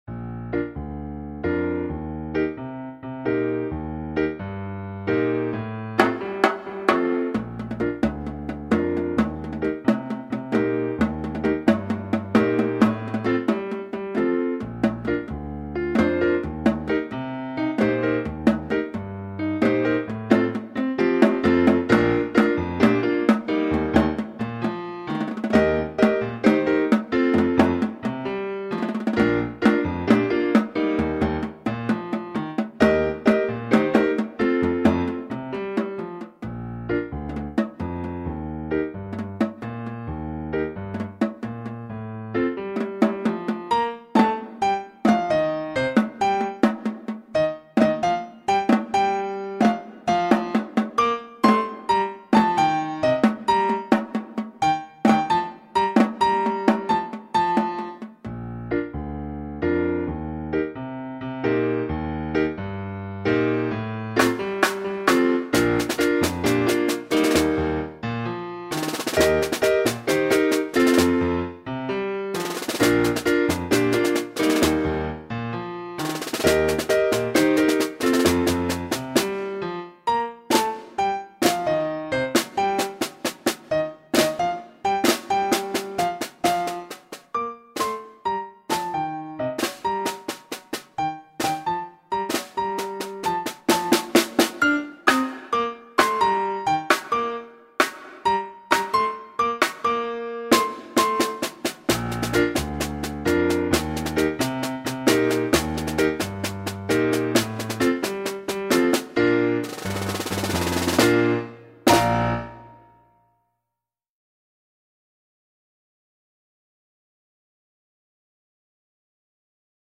07 Rudimental Rhumba – Piano et Caisse claire
07-Rudimental-Rhumba-Piano-et-Caisse-claire.mp3